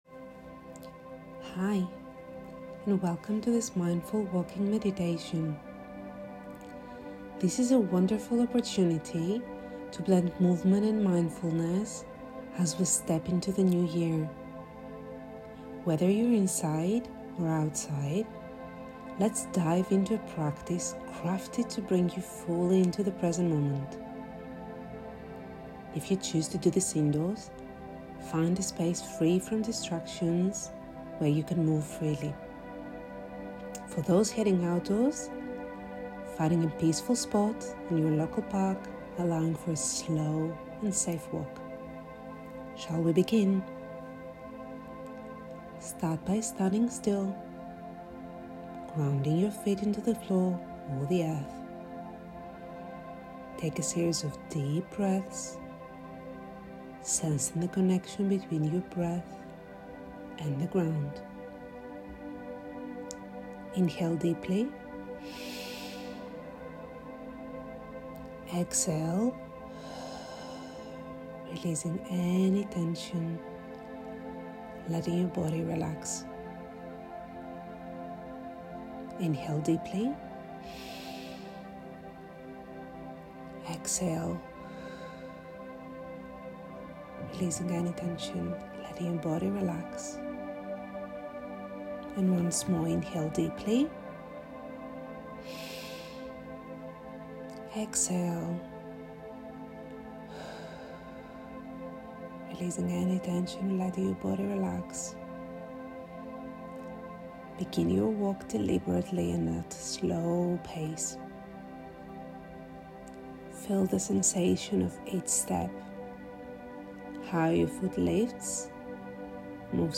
This guided meditation encourages you to connect with the present moment.